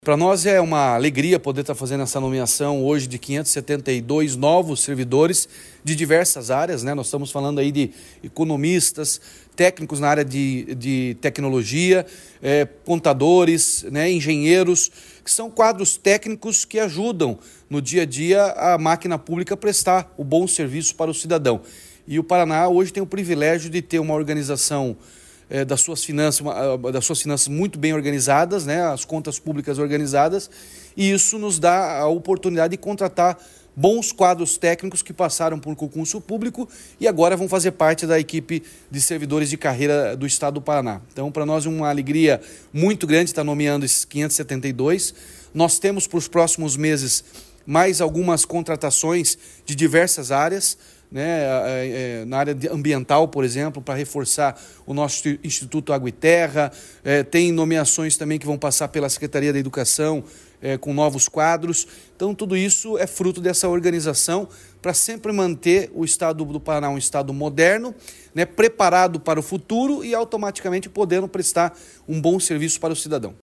Sonora do governador Ratinho Junior sobre a nomeação de 572 novos servidores para reforçar o quadro do Poder Executivo